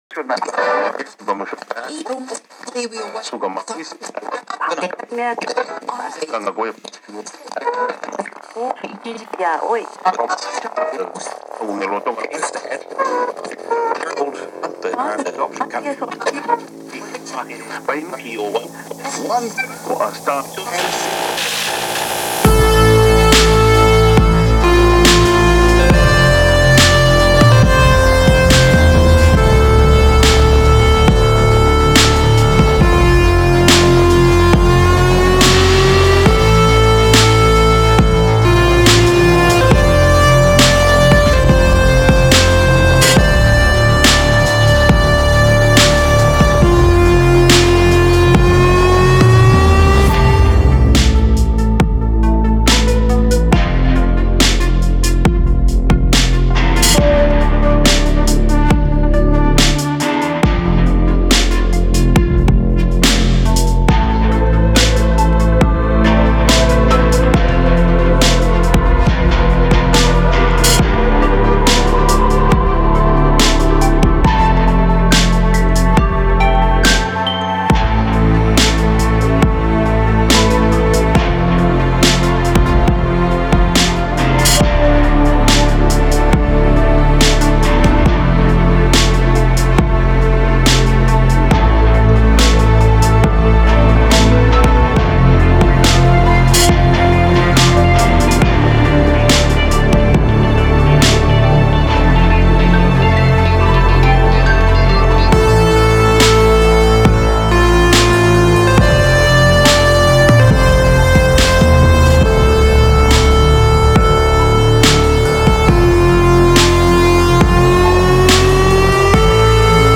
inspiré de l’univers synthwave, cyberpunk